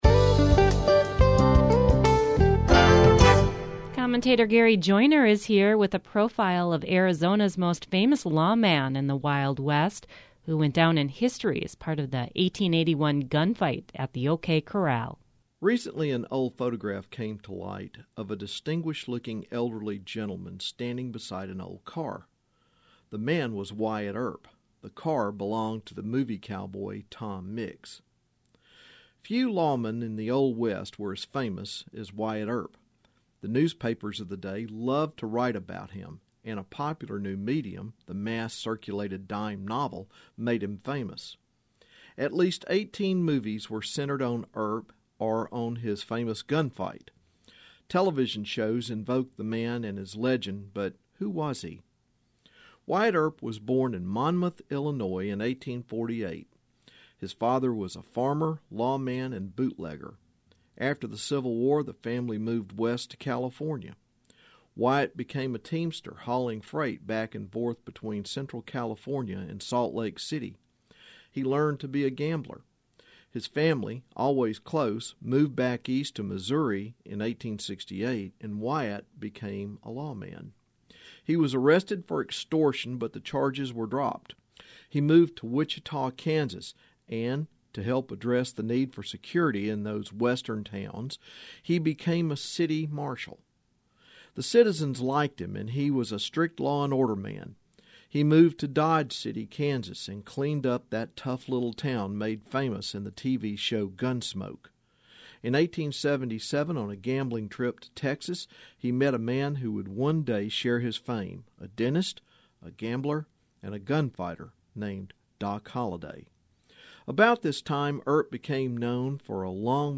History Matters #421 - Wyatt Earp commentary